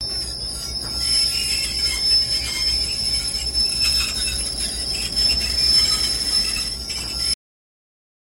Выпуск пара паровозом